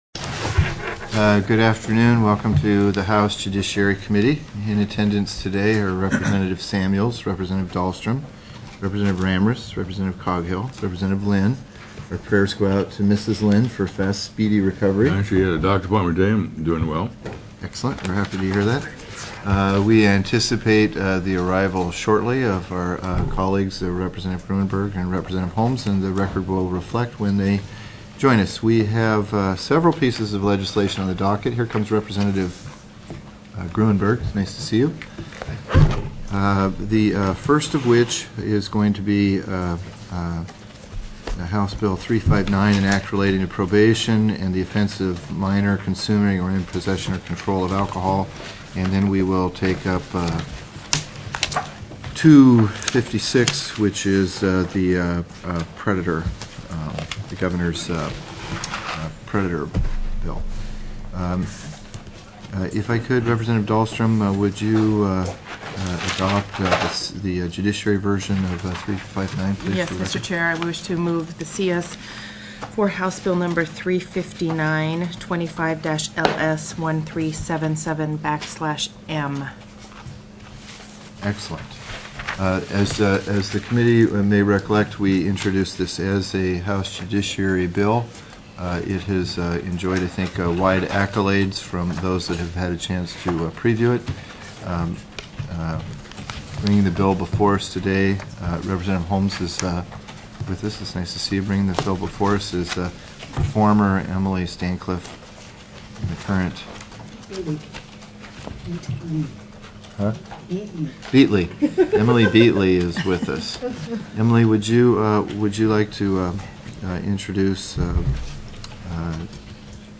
02/20/2008 01:00 PM House JUDICIARY